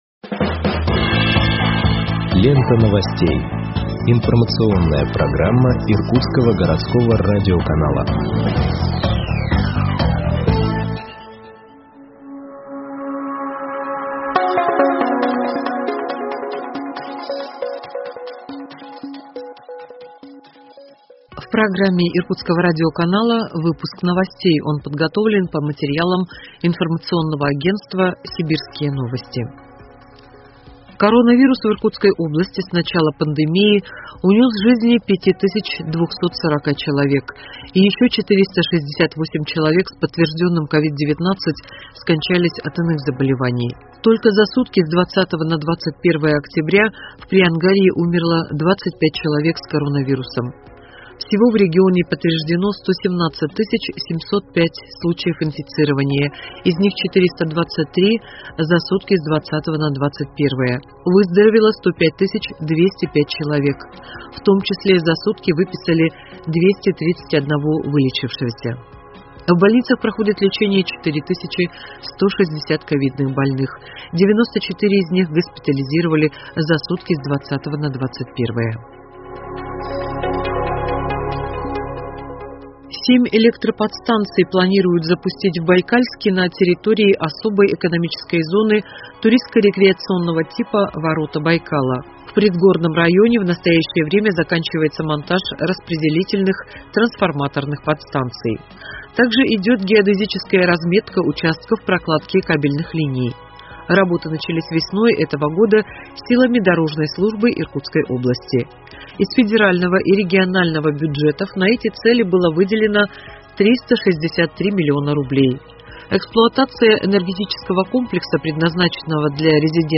Выпуск новостей в подкастах газеты Иркутск от 22.10.2021 № 2